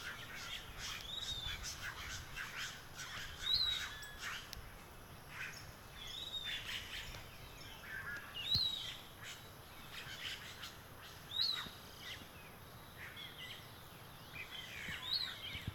Bem-te-vi-pirata (Legatus leucophaius)
Nome em Inglês: Piratic Flycatcher
Localidade ou área protegida: Parque Provincial Urugua-í
Condição: Selvagem
Certeza: Observado, Gravado Vocal